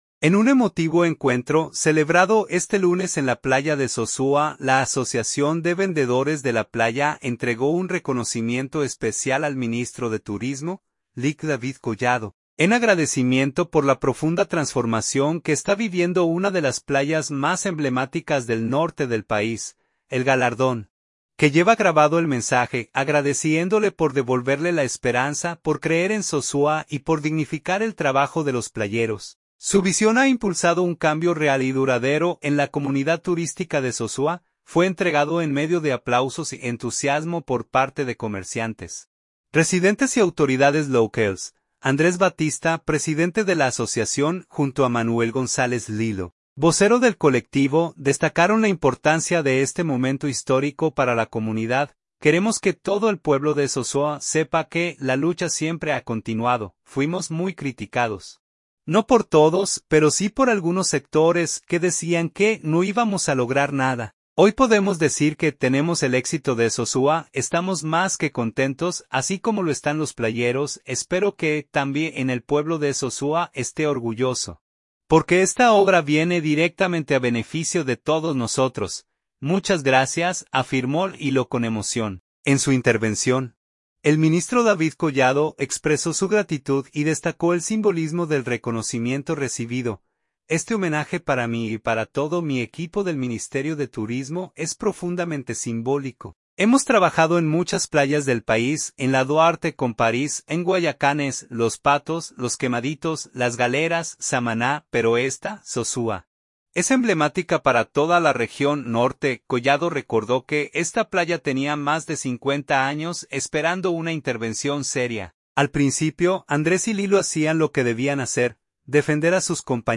Sosúa, Puerto Plata.– En un emotivo encuentro celebrado este lunes en la Playa de Sosúa, la Asociación de Vendedores de la Playa entregó un reconocimiento especial al Ministro de Turismo, Lic. David Collado, en agradecimiento por la profunda transformación que está viviendo una de las playas más emblemáticas del norte del país.
El galardón, que lleva grabado el mensaje: "Agradeciéndole por devolverle la esperanza, por creer en Sosúa y por dignificar el trabajo de los playeros; su visión ha impulsado un cambio real y duradero en la comunidad turística de Sosúa", fue entregado en medio de aplausos y entusiasmo por parte de comerciantes, residentes y autoridades locales.